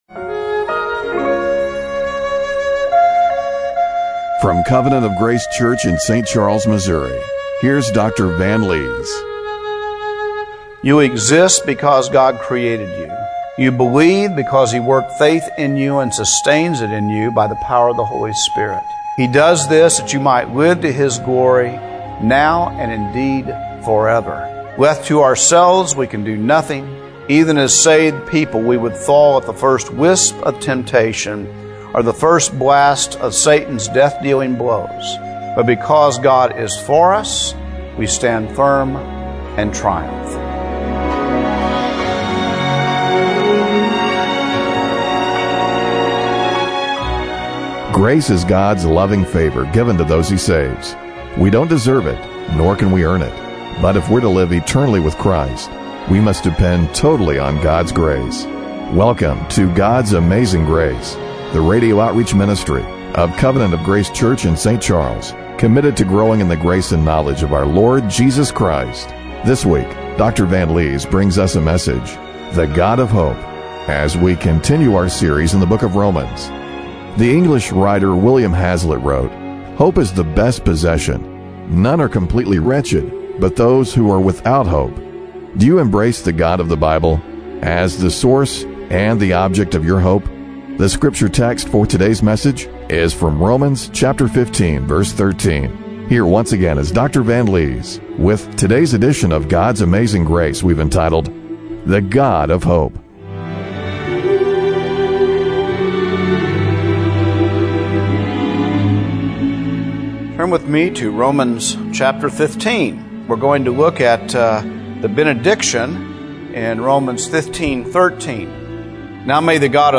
Romans 15:13 Service Type: Radio Broadcast Do you embrace the God of the Bible as the source and the object of your hope?